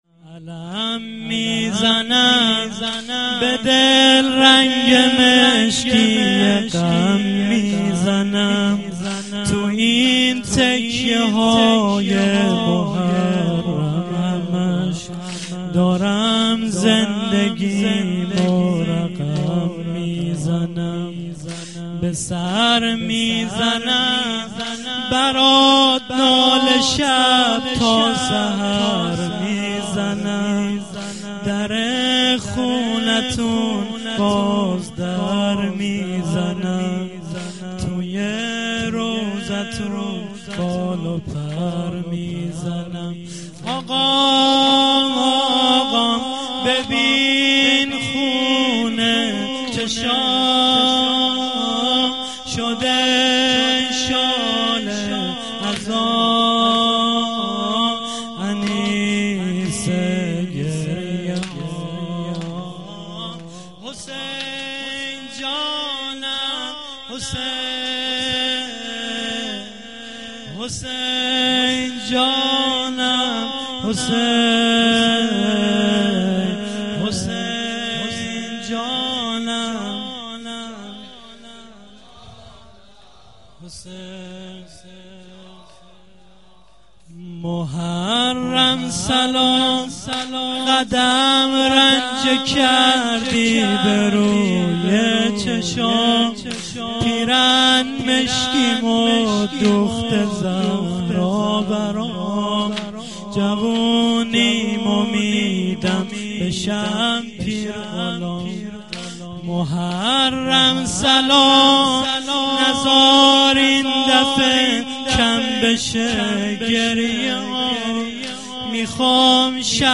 مراسم هفتگی 95.07.04
شور